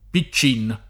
pi©©&n], Piccini — cfr. picchino